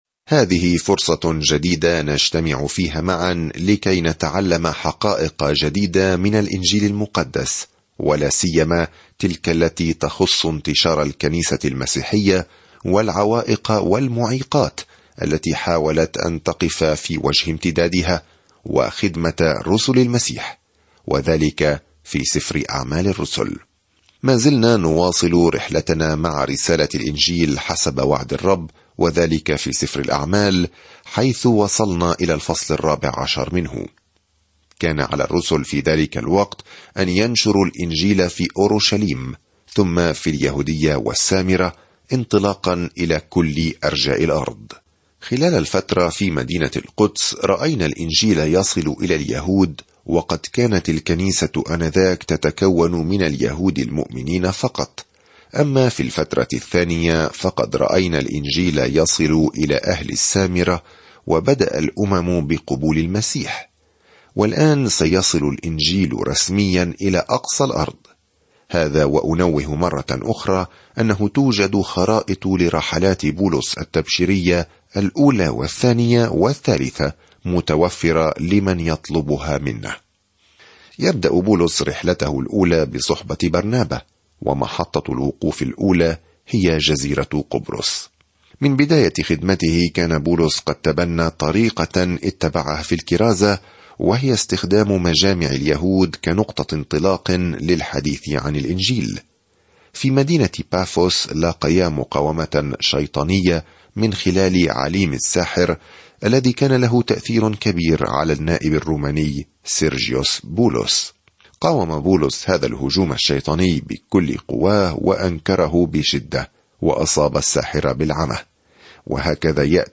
Scripture Acts 14 Day 16 Start this Plan Day 18 About this Plan يبدأ عمل يسوع في الأناجيل ويستمر الآن من خلال روحه، حيث تُزرع الكنيسة وتنمو في جميع أنحاء العالم. سافر يوميًا عبر سفر أعمال الرسل وأنت تستمع إلى الدراسة الصوتية وتقرأ آيات مختارة من كلمة الله.